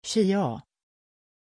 Pronunciation of Kiah
pronunciation-kiah-sv.mp3